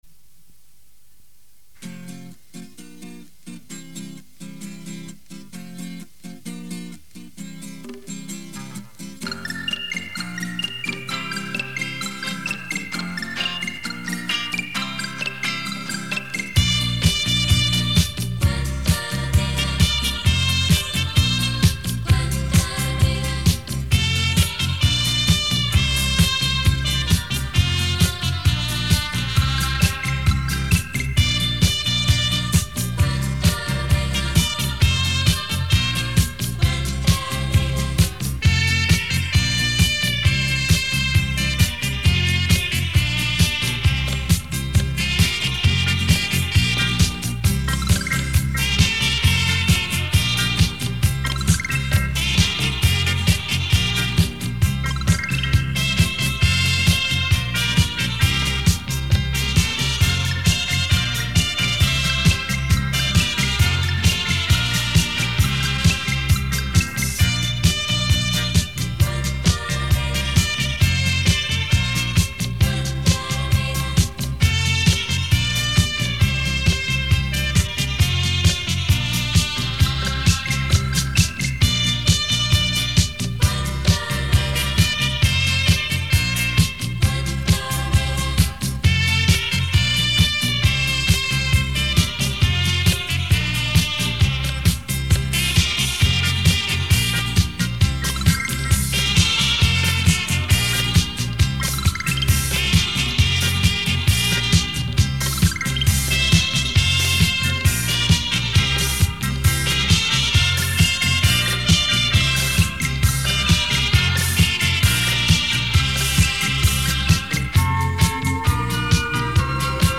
第一集演奏集
类型：纯音乐